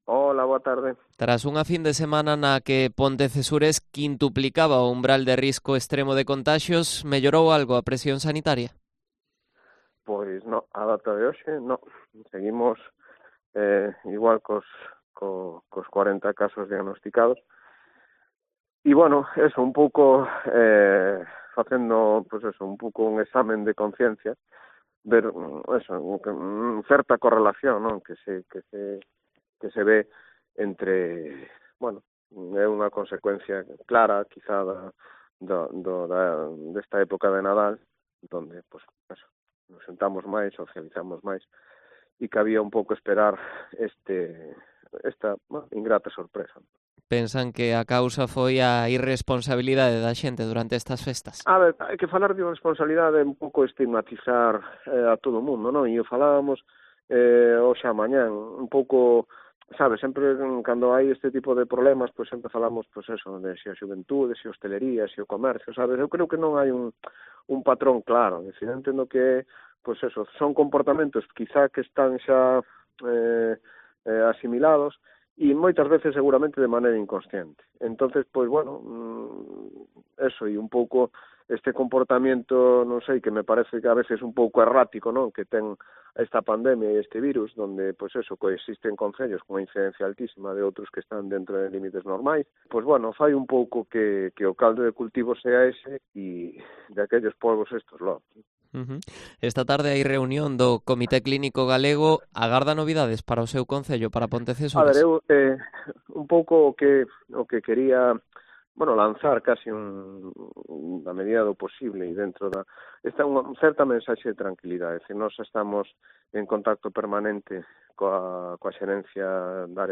Entrevista Juan Manuel Vidal Seage, alcalde de Pontecesures